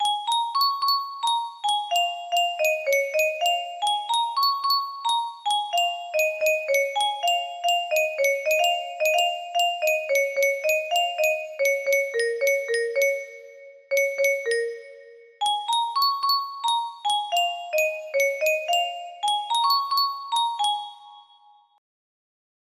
Grand Illusions 30 (F scale)